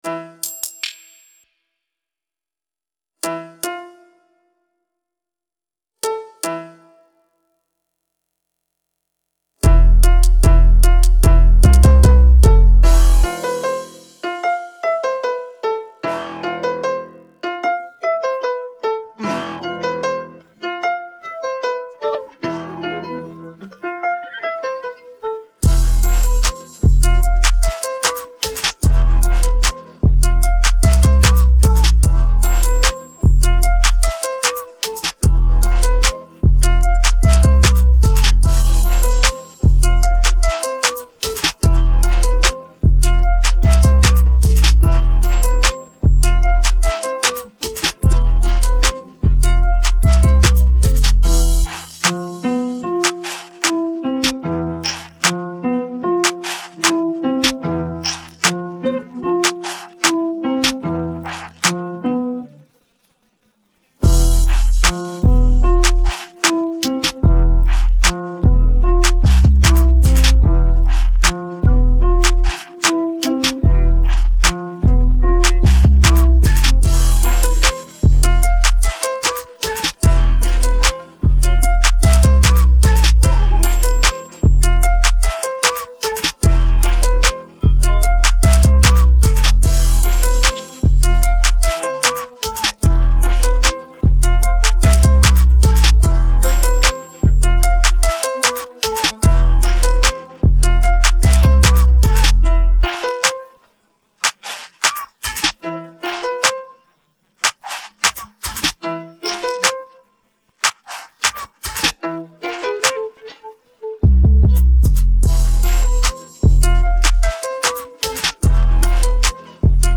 Taustamusiikki